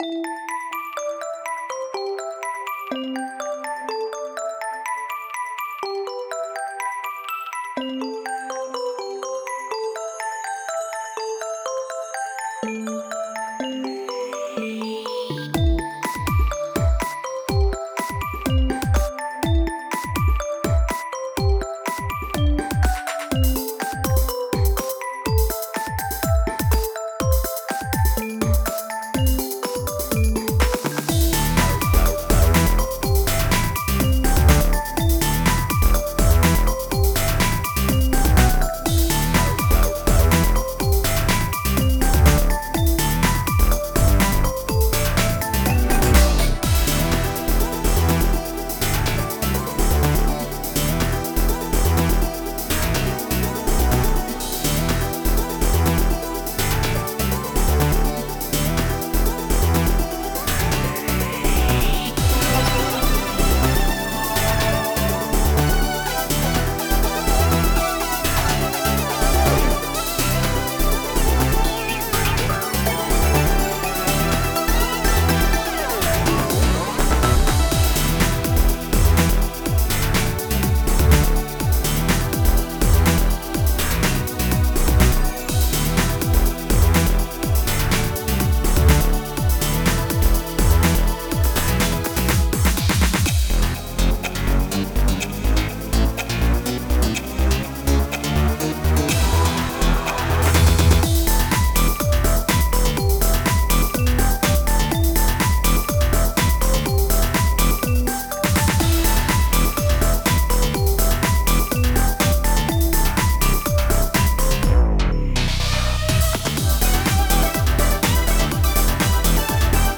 Stil: Synthpop / Dance
Ein weiterer peppiger Track